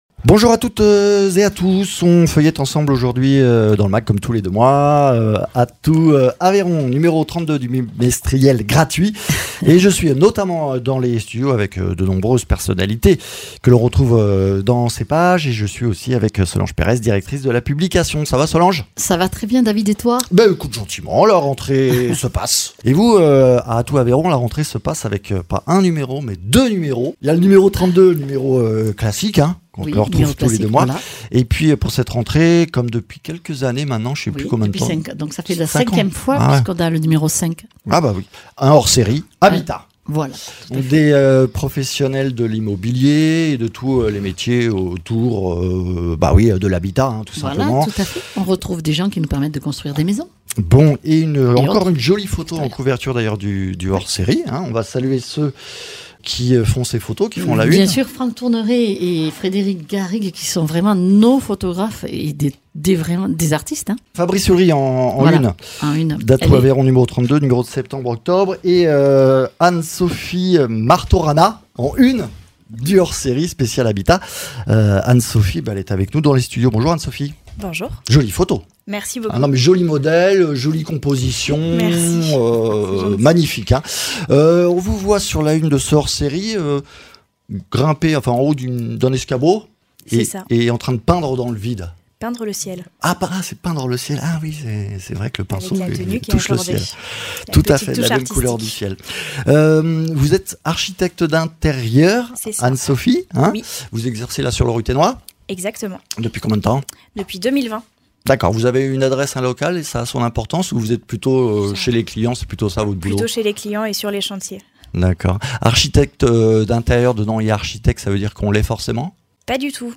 Un hors série Habitat et le numéro de rentrée d’Atout Aveyron, nous recevons quelques unes des personnalités à découvrir dans le bimestriel gratuit